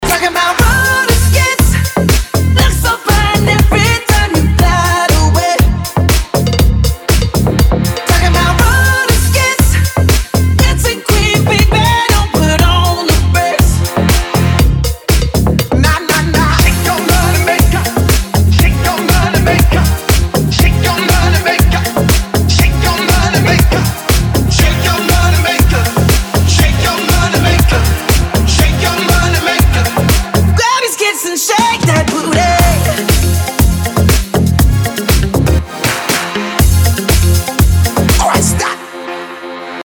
• Качество: 320, Stereo
dance
house